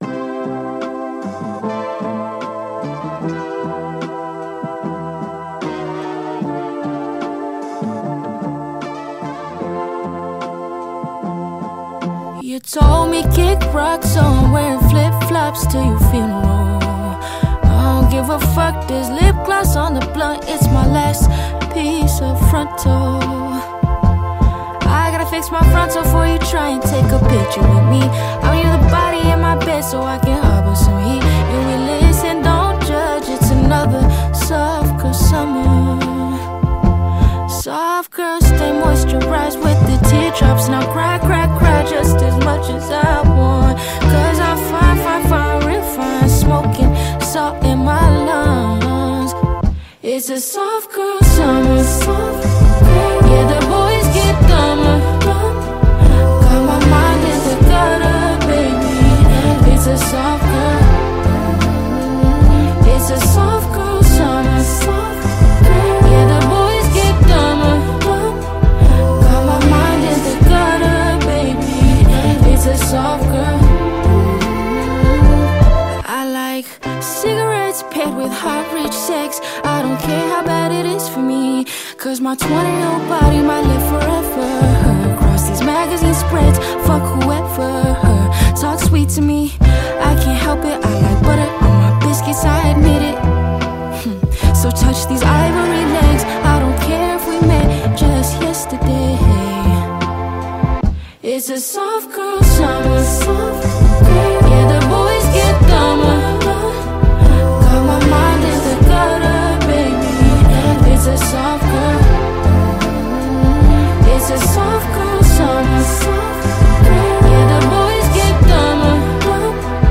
американская певица и автор песен.